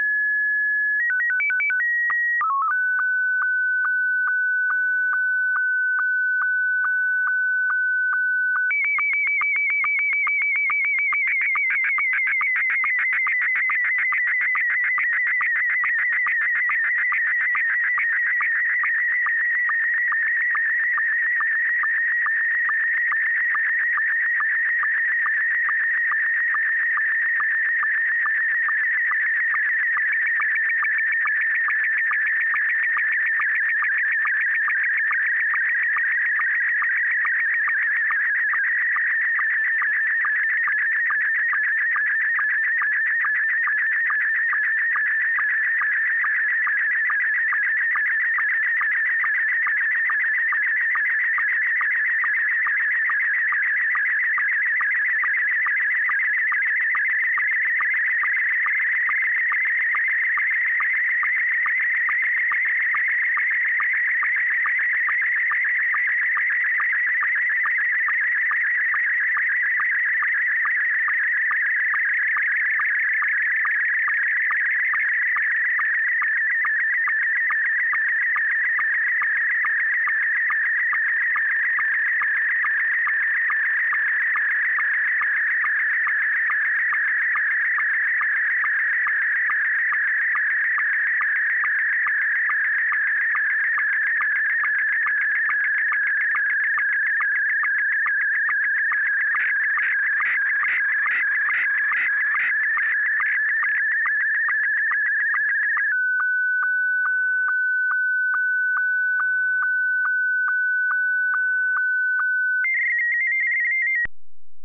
So launching QSSTV which is a tool for receiving and transmiting images over radio using analog SSTV or digital DRM, then loading the audio file prints the image.